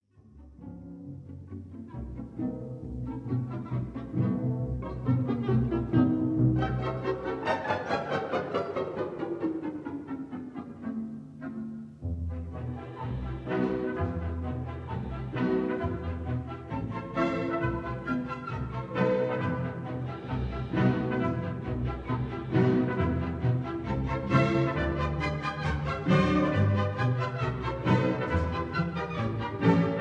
This is a 1958 stereo recording
ballet